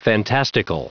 Prononciation du mot fantastical en anglais (fichier audio)
Prononciation du mot : fantastical